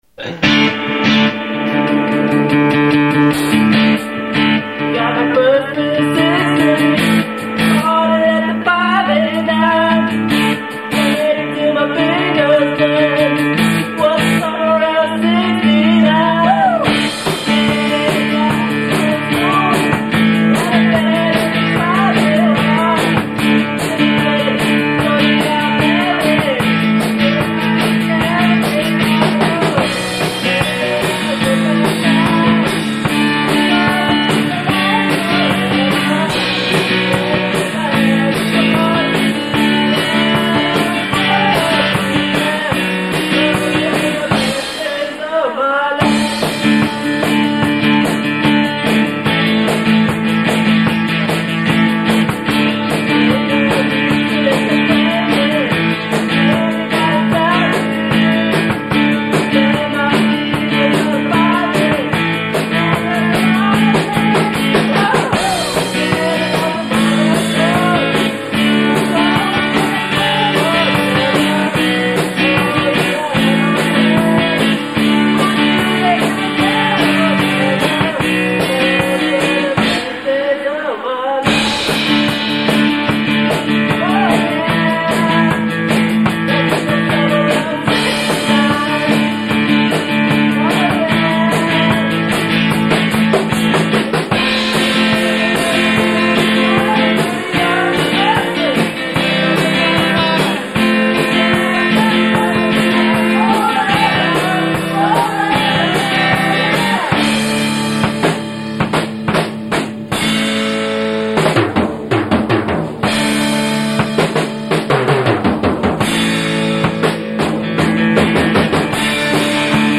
They are completely and unapologetically live.
I actually consider it quite tolerable.